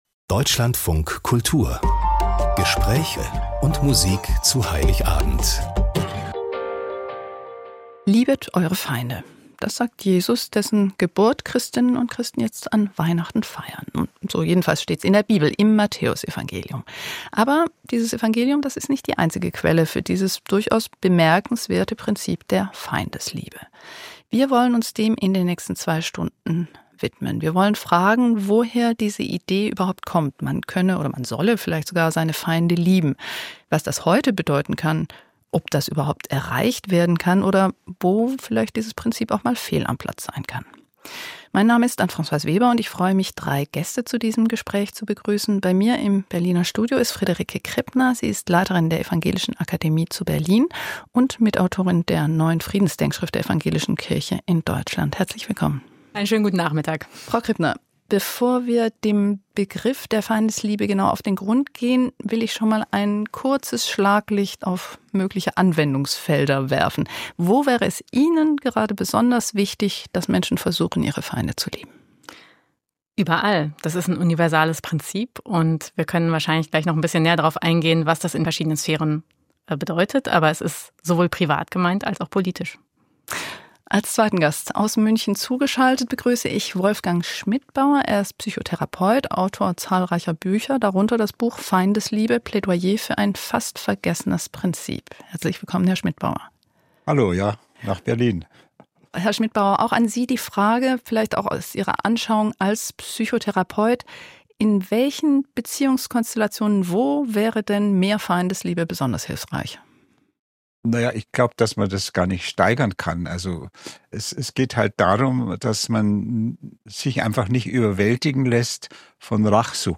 Wie wir das wieder lernen können, diskutieren ein Psychotherapeut, eine Theologin und ein Dialogmoderator.